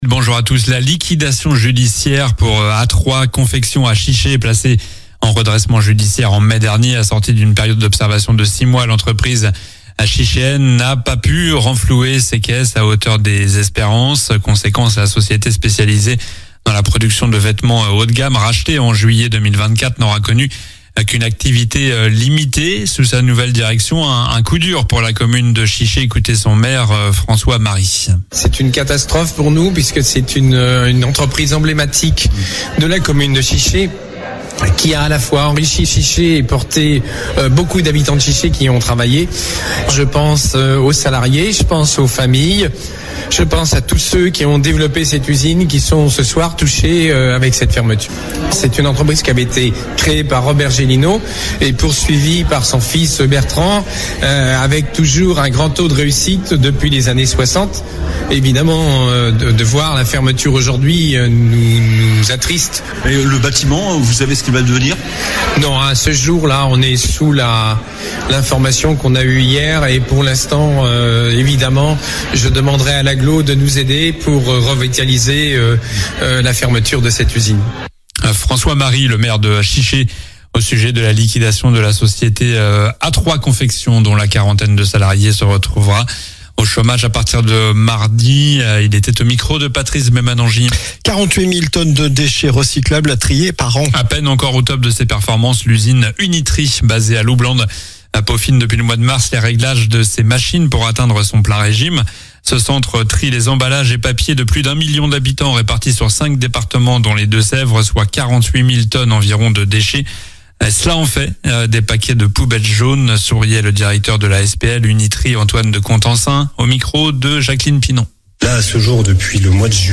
Journal du samedi 29 novembre